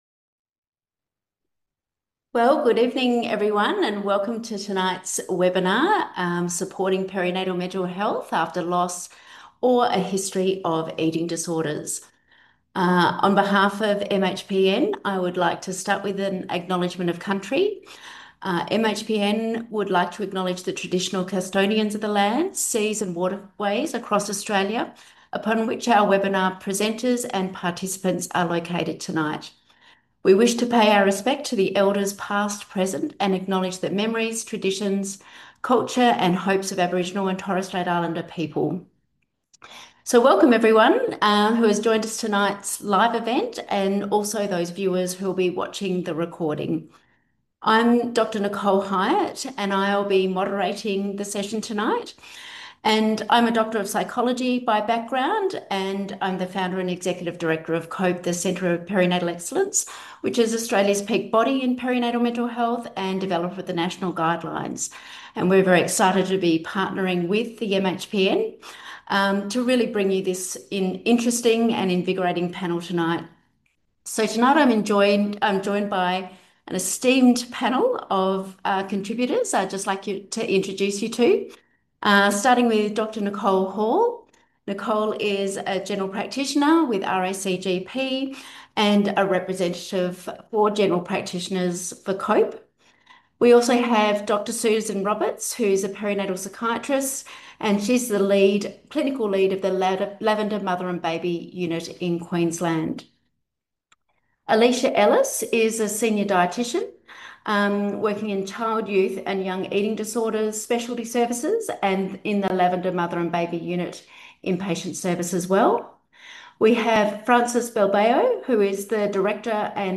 Focused on perinatal mental health and the impact of grief and a history of eating disorders, this webinar was presented in collaboration with COPE (Centre of Perinatal Excellence), Australia’s peak body in perinatal mental health.